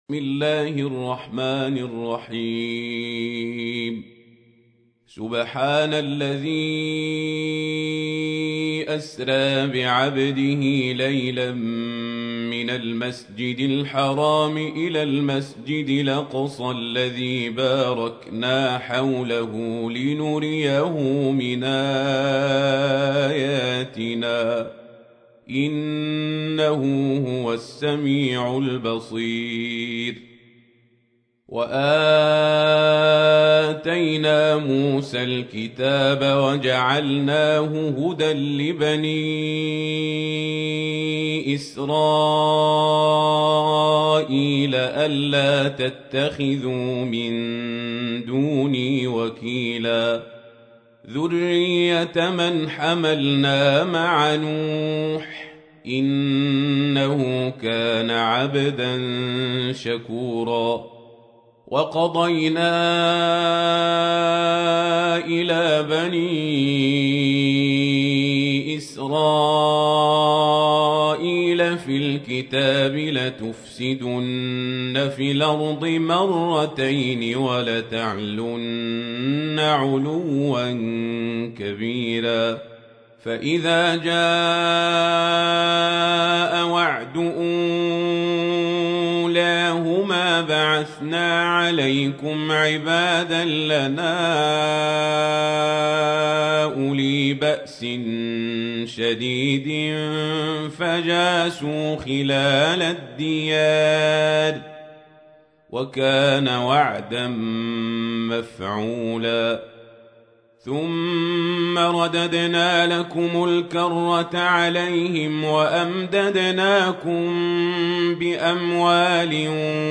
تحميل : 17. سورة الإسراء / القارئ القزابري / القرآن الكريم / موقع يا حسين